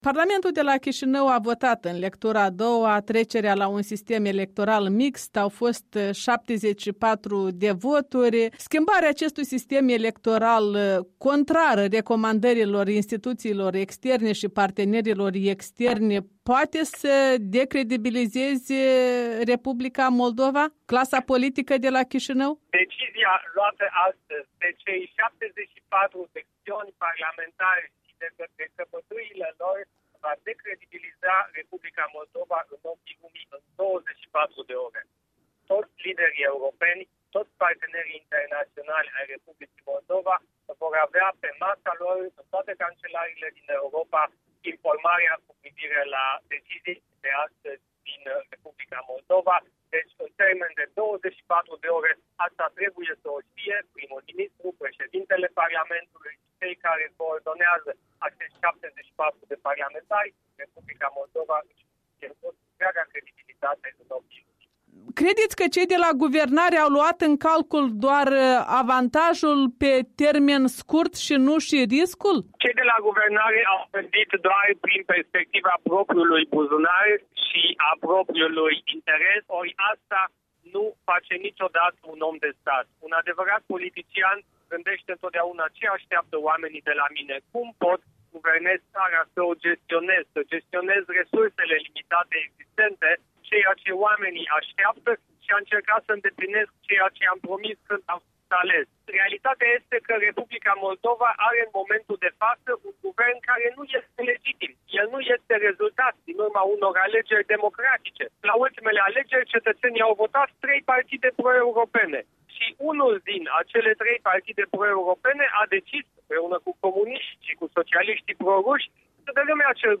Interviu cu europarlamentarul Siegfried Mureșan